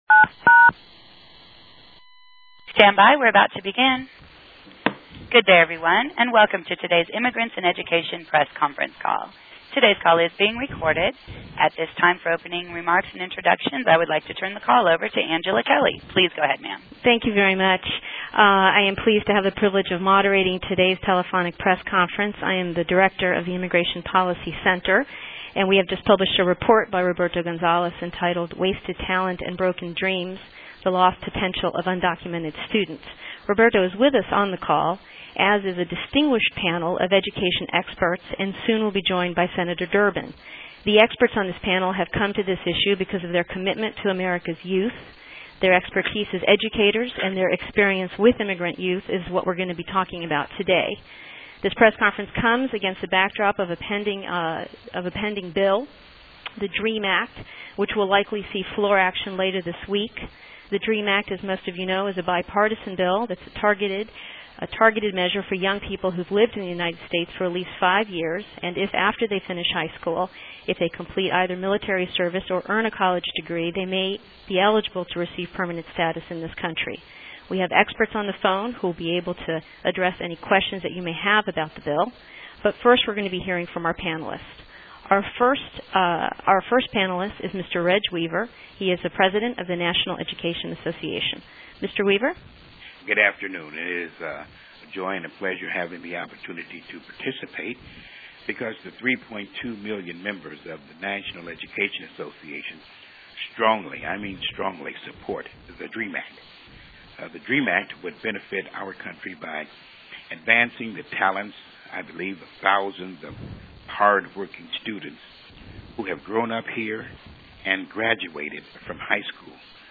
On the record briefing with Q&A on the DREAM Act, plus a new report on the cost of not educating DREAM Act students
DREAM teleconference.mp3